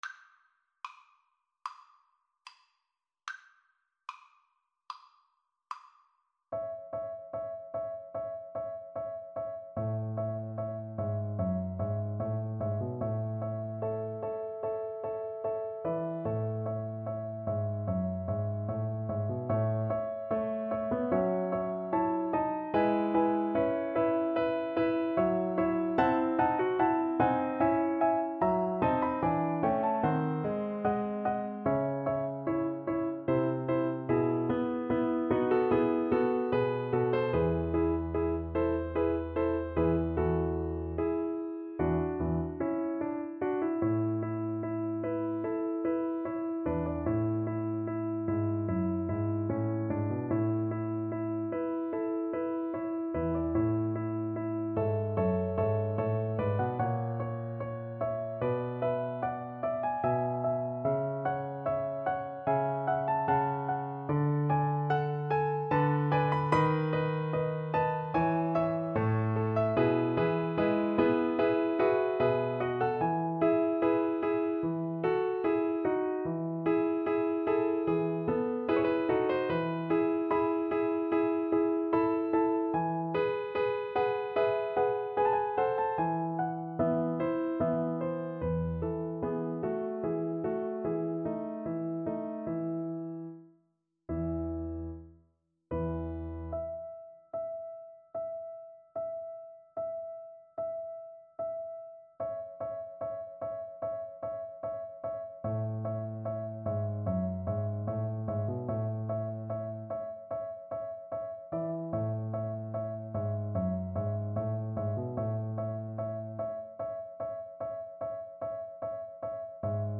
~ = 74 Moderato
Classical (View more Classical Viola Music)